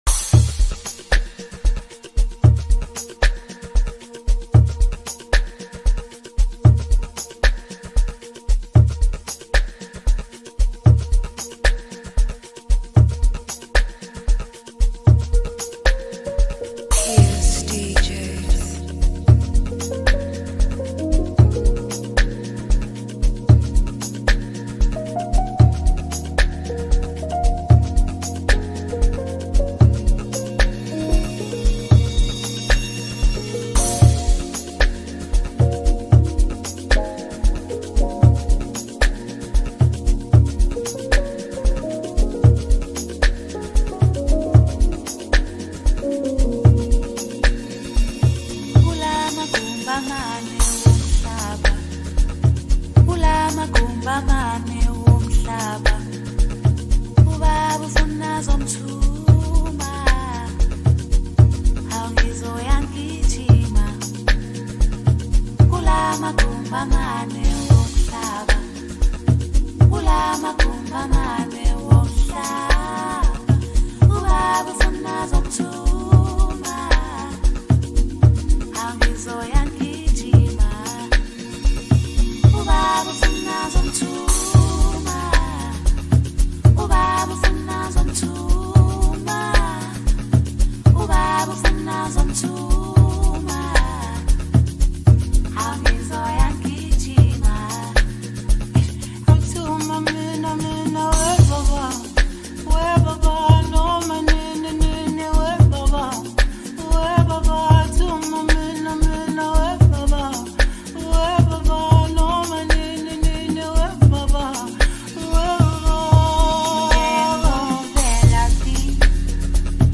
Th djing duo come through with their latest mixtape